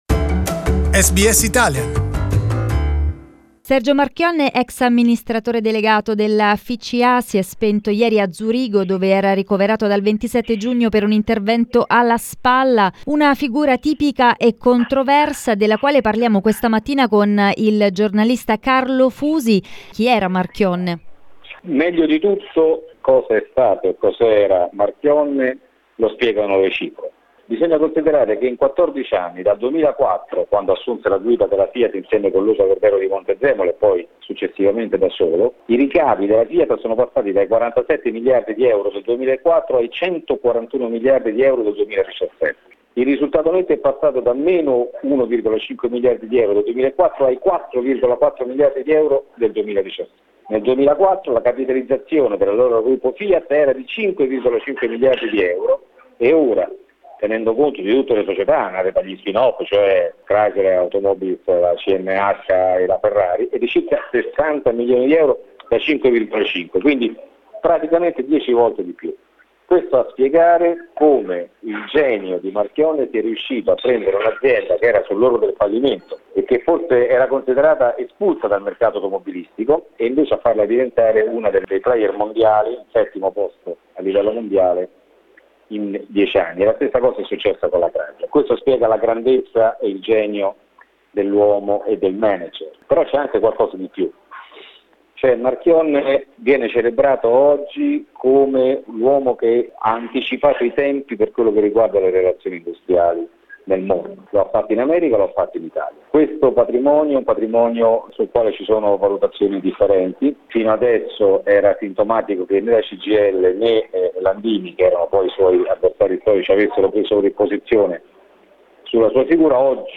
Abbiamo raggiunto in Italia il giornalista